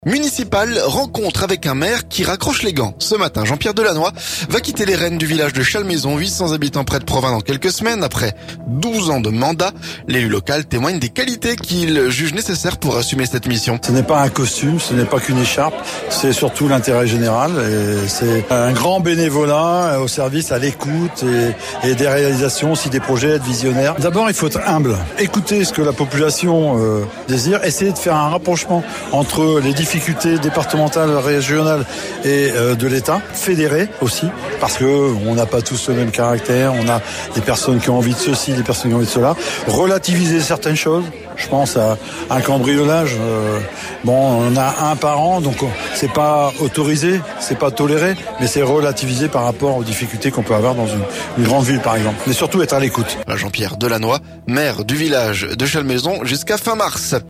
L'élu local témoigne des qualités qu'il faut pour assumer cette mission.